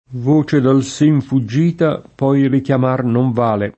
ve dal S%n fuJJ&ta p0i rikLam#r non v#le] (Metastasio); Le braccia al sen conserte [le br#©©a al S%j konS$rte] (Manzoni) — sim. il cogn. S.